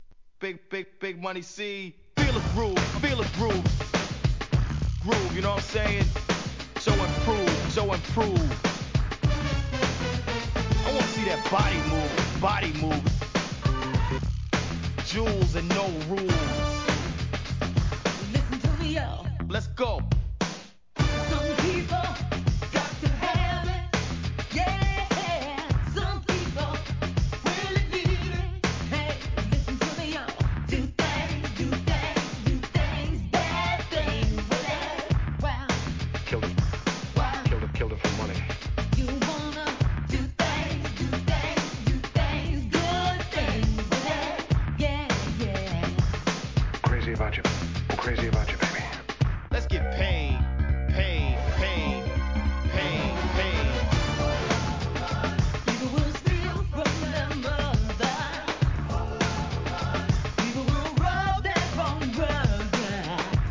HIP HOP/R&B
NEW JACK SWINGな内容、1989年アルバム!